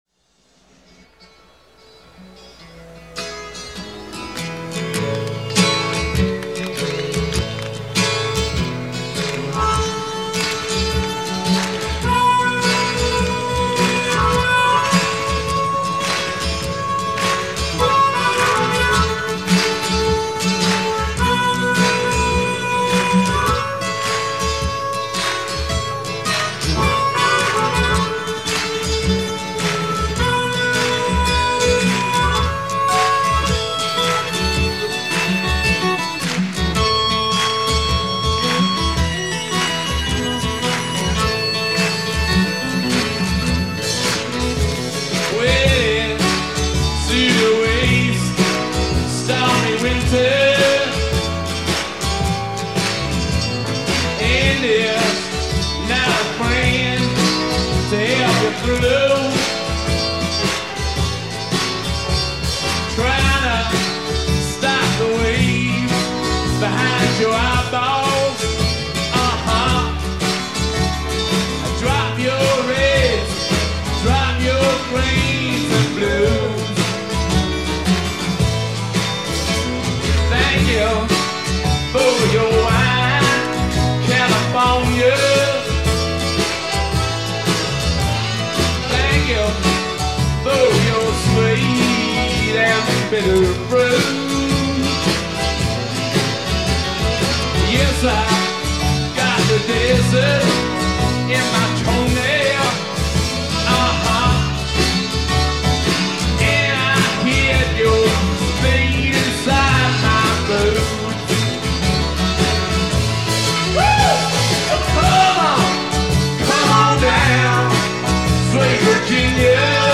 Live '72